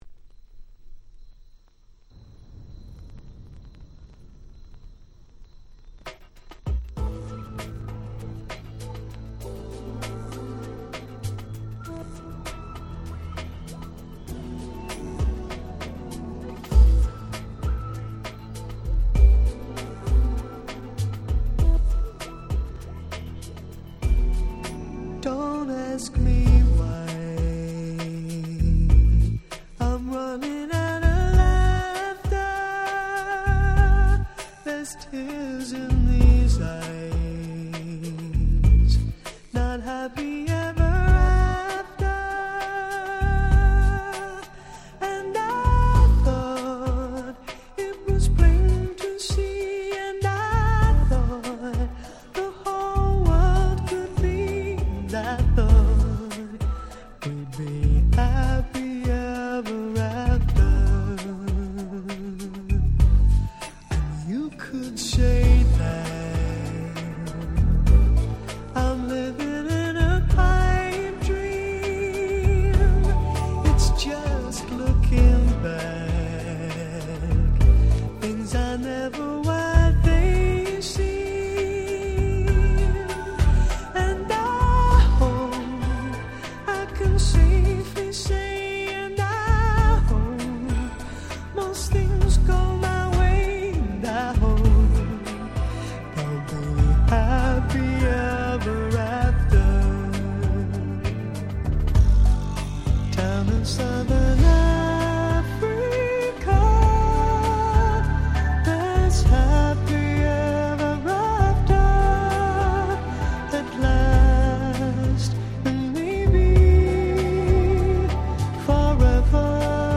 このコンピの特徴は「音が良い」事。
夏にぴったりなPop Reggaeヒット4曲収録で超お得！